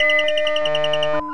hacking_phone_modem_beeps_01.wav